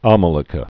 mə-lə-kə)